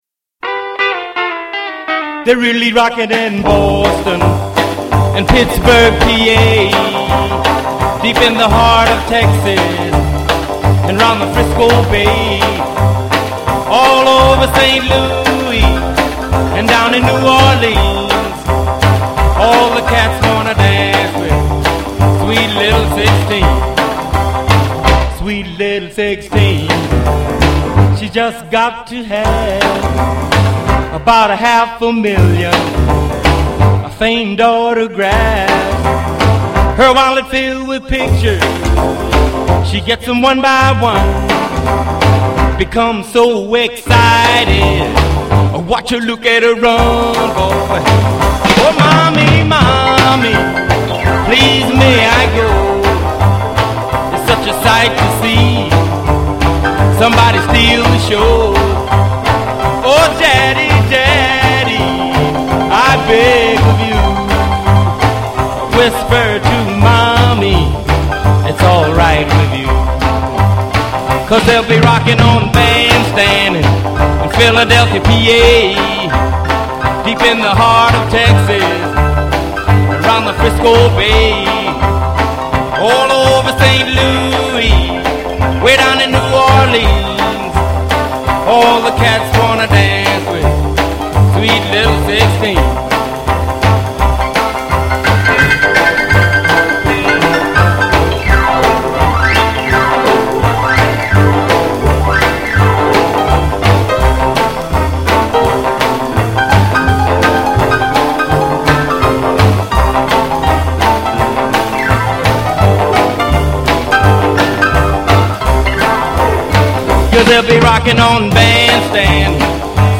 That’s not the fault of our numerous guests, I just wasn’t “on” this week.
Anyway, we’ve got a pretty decent interview and some lackluster PPV coverage.